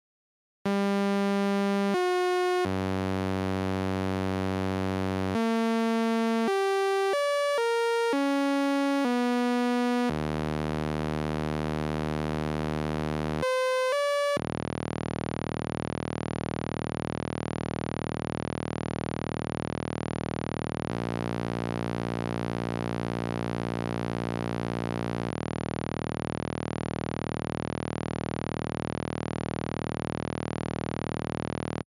This week I made a circuit board that talks to a speaker.
I couldn't figure out why not, so In the end I finally opted for just writing some arbitrary melody in to the main execution loop using _delay_ms() instead of the sample rate interrupt.
I think that has to do with the pwm style of synthesis, and it should be pushed out of the audible range by setting the clock divider to 1 instead of 8.